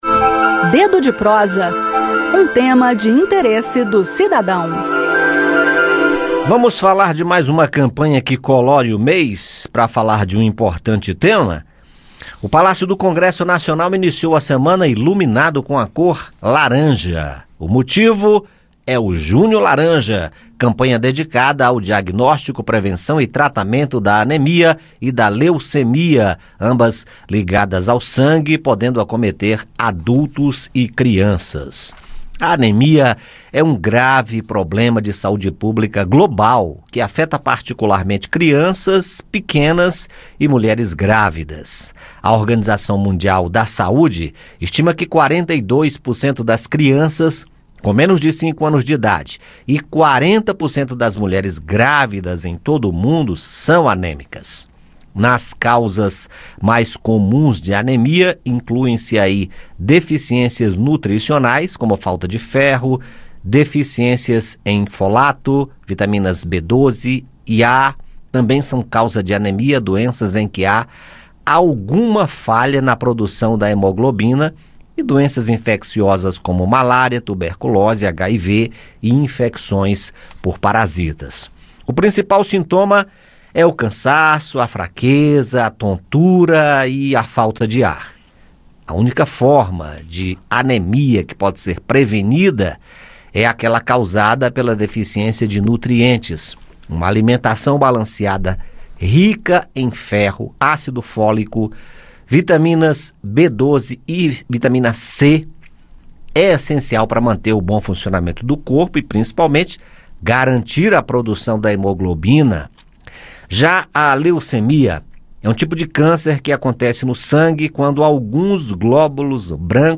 Ouça mais informações e saiba como prevenir essas patologias, no bate-papo com